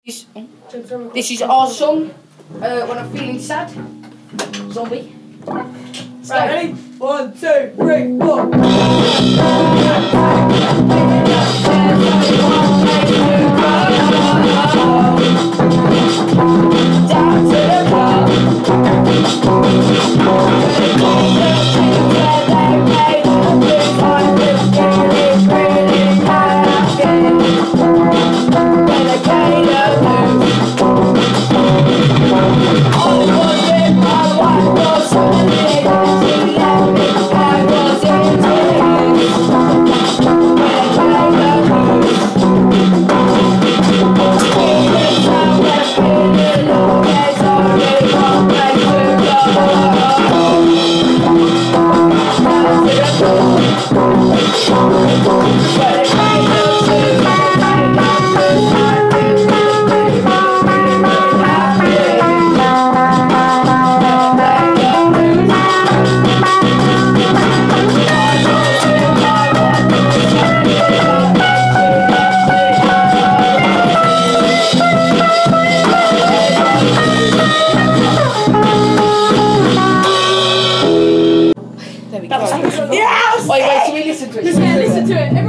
blues live amazing